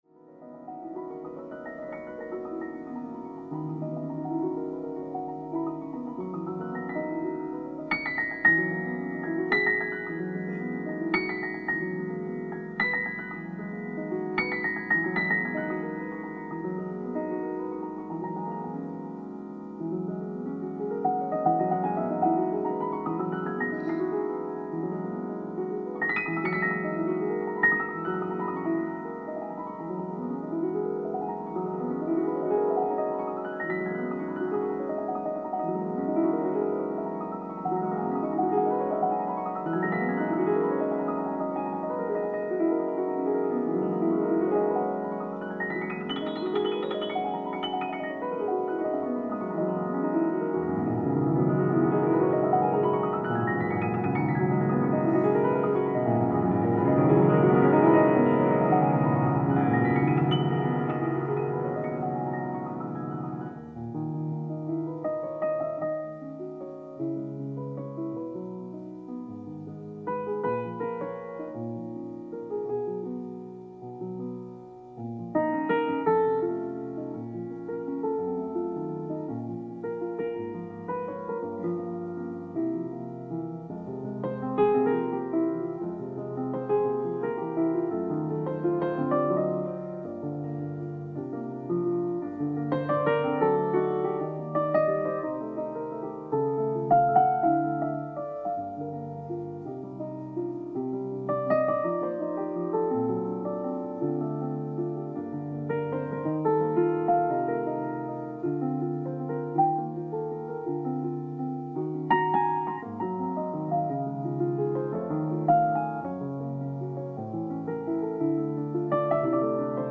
※試聴用に実際より音質を落としています。
Piano Improvisation 2.Encore(Flute Solo)